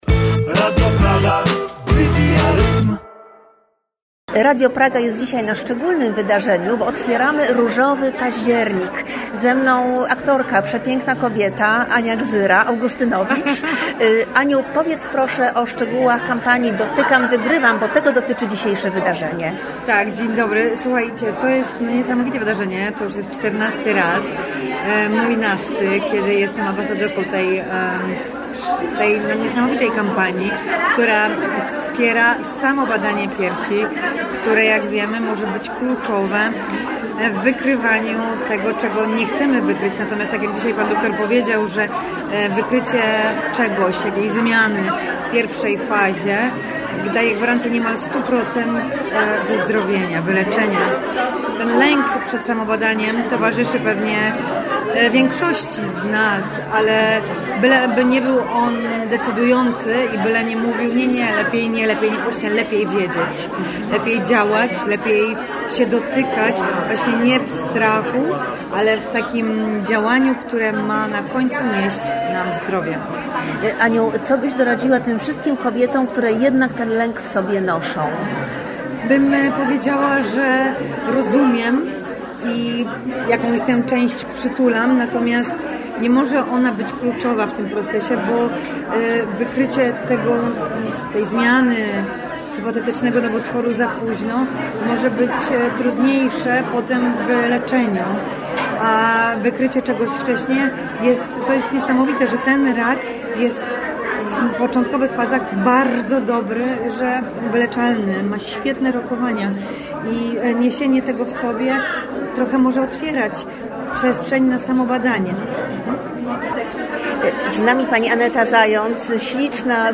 w specjalnej rozmowie